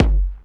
59 BD 2   -L.wav